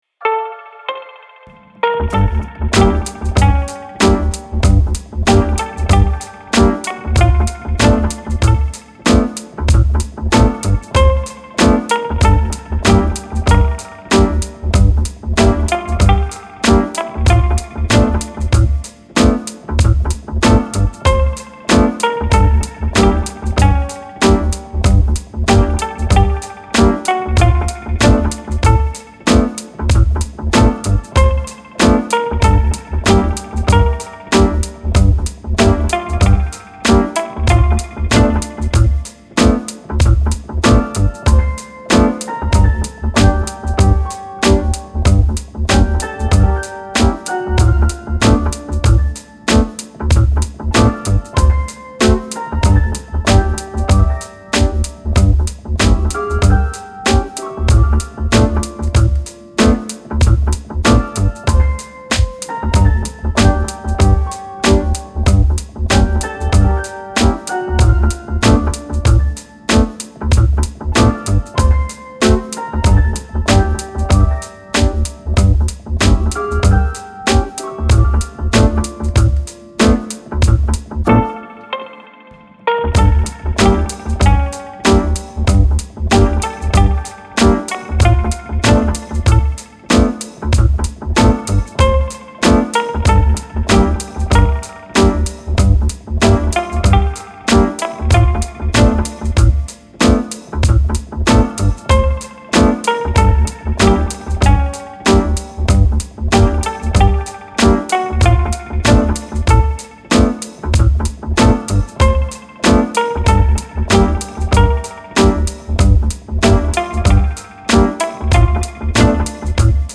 Bouncing bass hip hop beat with quirky melancholic melody.